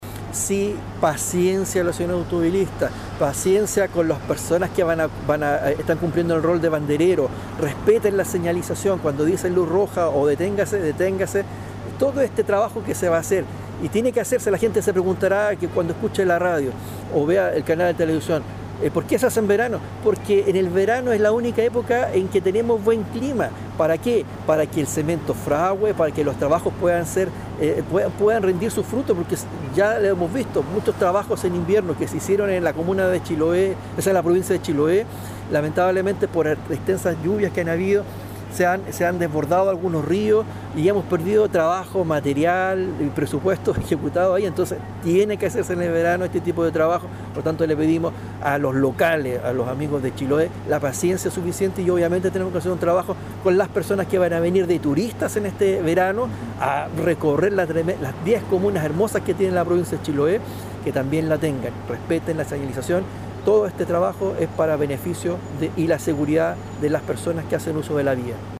Por ello es que la ruta se encuentra con el trabajo de bandereros en los lugares donde se encuentran los trabajos, los que de manera imperativa deben realizar en esta época del año, insistió el seremi de Obras Públicas, Juan Fernando Alvarado, quien pidió paciencia y colaboración a los automovilistas.